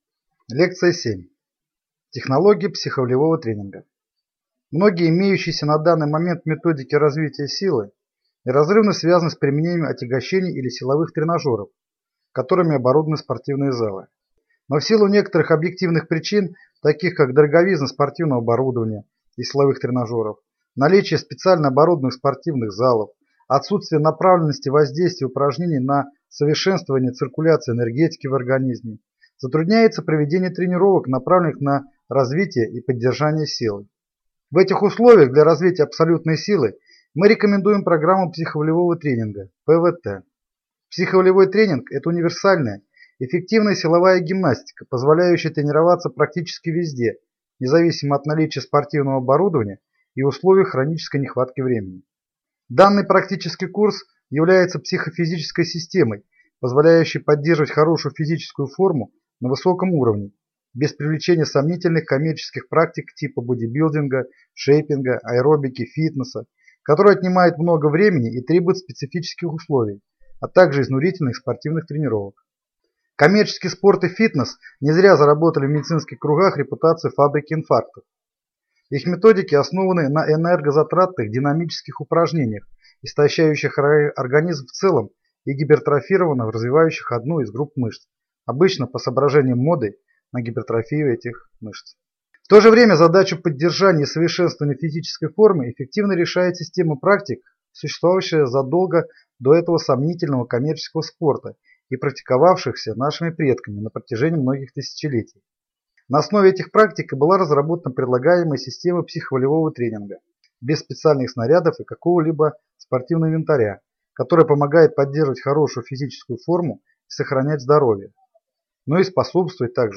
Аудиокнига Лекция 7. Технология психо-волевого тренинга | Библиотека аудиокниг